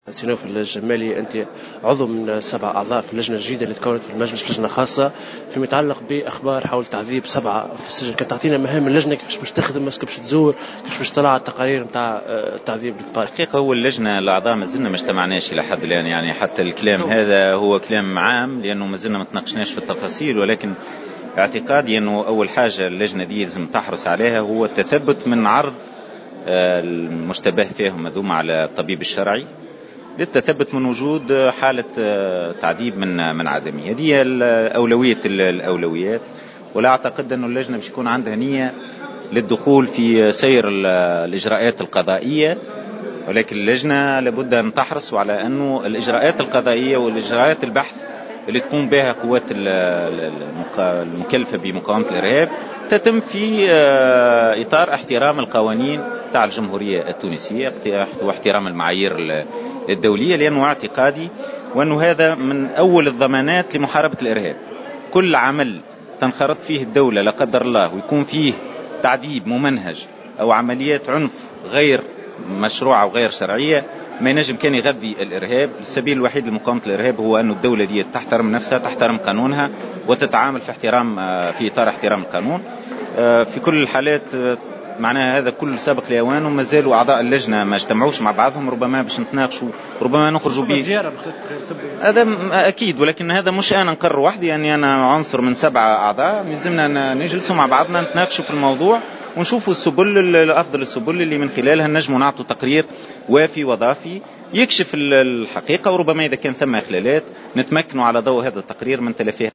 أكد النائب نوفل الجمالي في تصريح لمراسل جوهرة اف ام ان اللجنة التي تكونت حديثا...